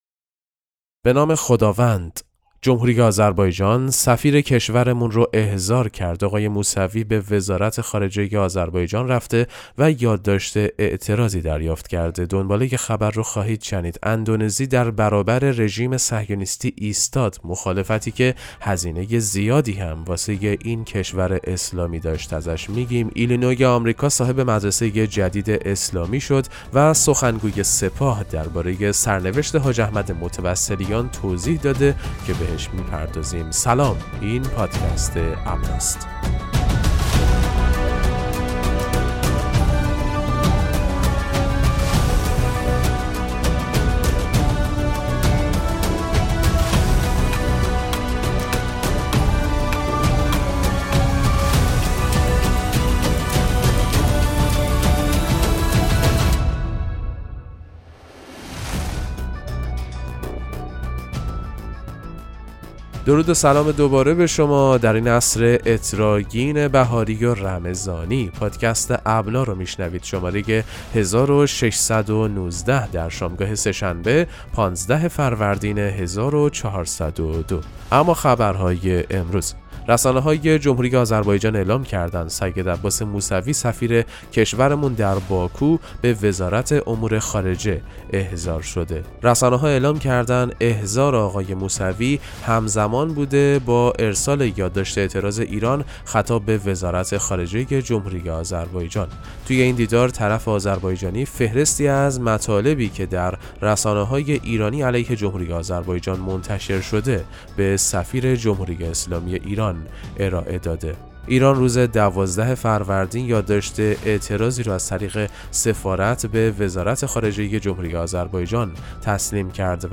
خبرگزاری اهل‌بیت(ع) ـ ابنا ـ با ارائه سرویس «پادکست مهم‌ترین اخبار» به مخاطبان خود این امکان را می‌دهد که در دقایقی کوتاه، از مهم‌ترین اخبار مرتبط با شیعیان جهان مطلع گردند.